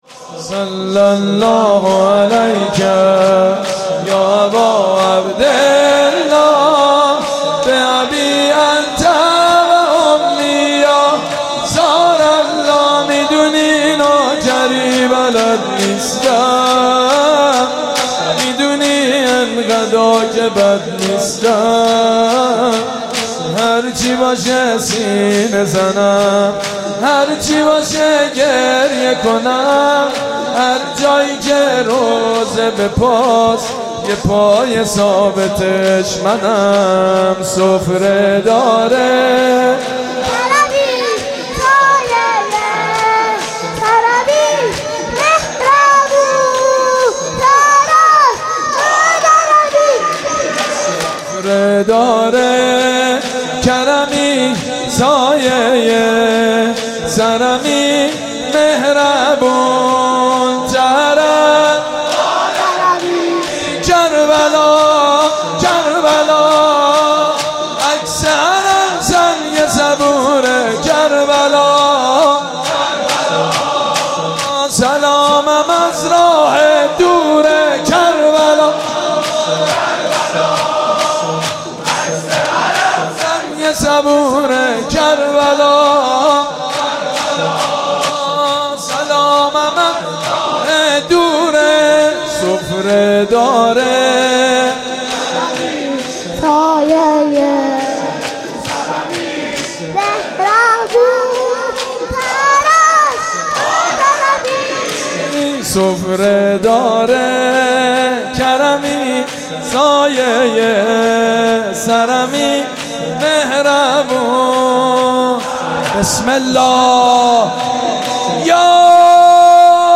صلی الله علیک یا اباعبدالله | شور